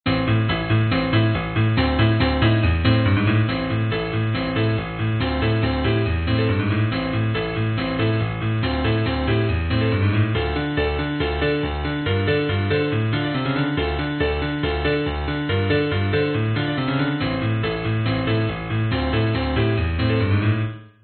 爵士乐的钢琴循环
描述：一个时髦的/爵士乐的钢琴循环。
Tag: fmc12 时髦 器乐 循环 钢琴